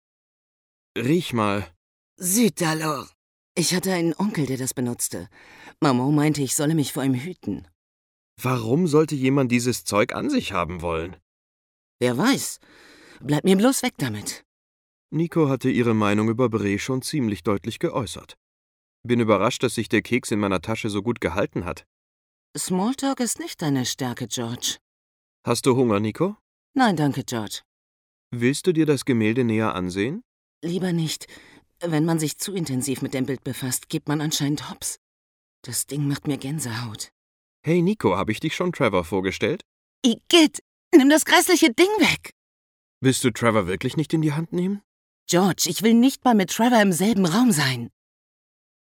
Hörbeispiel, Interviews, Screenshots, Zum Projekt, Box vorbestellen, Collectors Edition vorbestellen)
bf5-stimmen.mp3